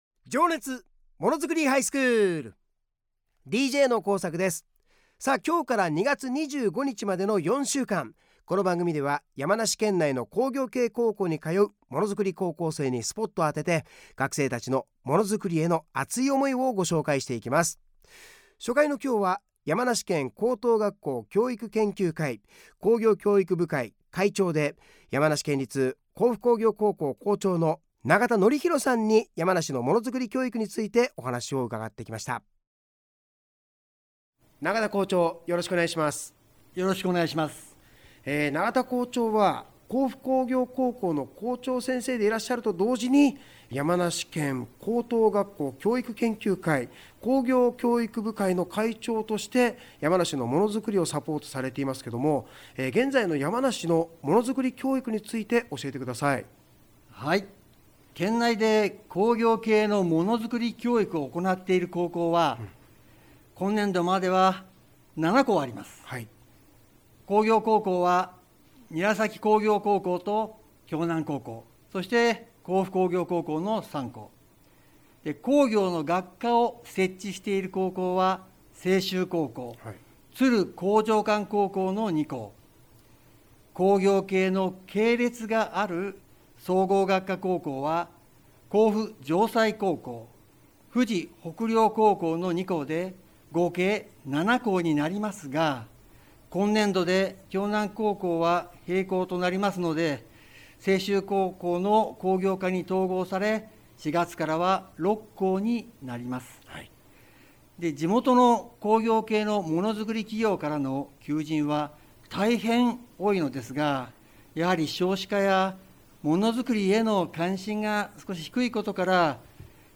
山梨のものづくり教育」についてお話を伺いました。